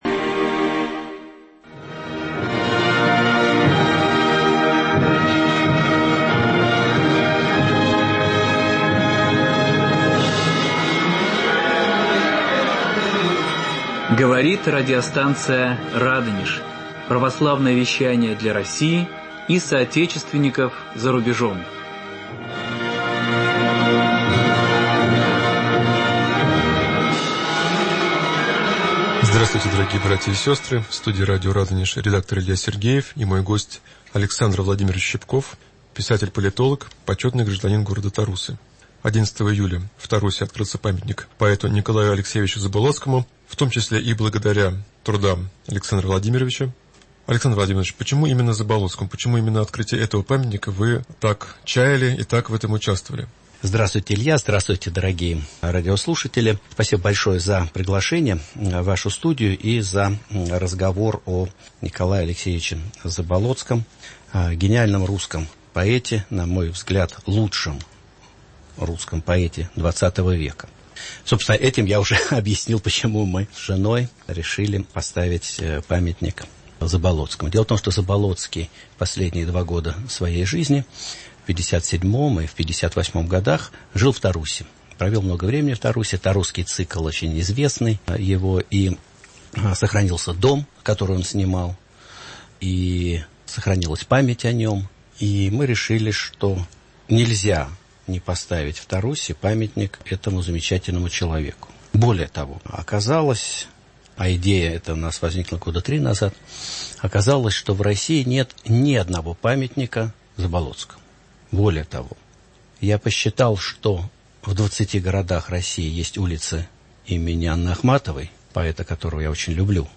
Беседа с писателем-политологом, почётным гражданином г. Тарусы А.В. Щипковым о поэте Н.А. Заболоцком.
Беседа с писателем-политологом, почётным гражданином г. Тарусы А.В. Щипковым о поэте Н.А. Заболоцком. В конце программы народный артист Советского Союза Н.П. Бурляев читает стихи Заблоцкого